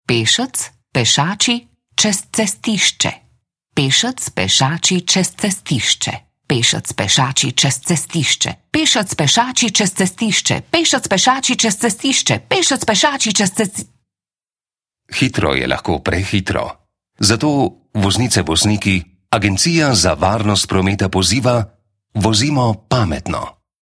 Radijski oglas Agencije za varnost prometa med prejemniki nagrad 26. SOF-a